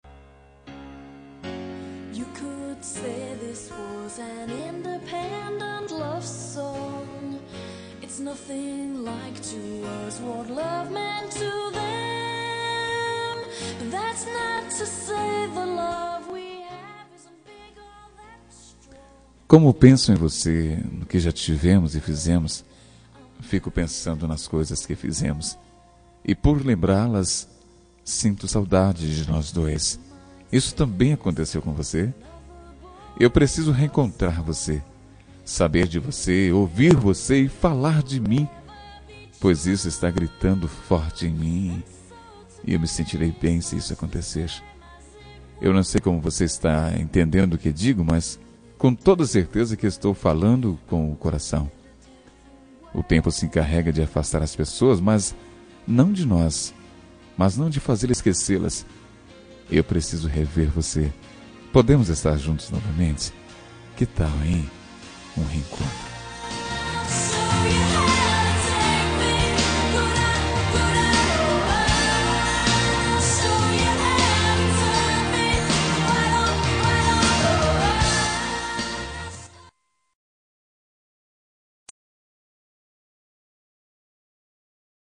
Telemensagem de Saudades – Voz Masculina – Cód: 447
447-saudades-masc-1.m4a